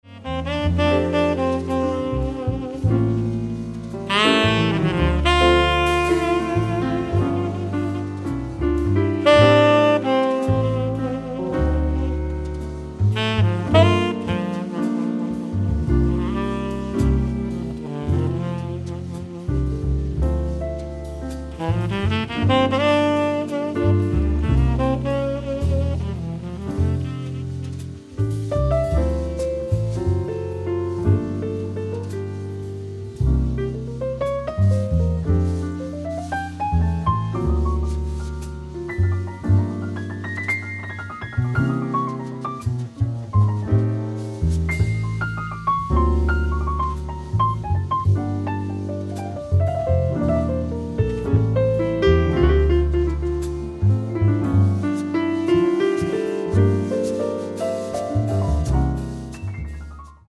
piano
tenor saxophone
acoustic bass
drums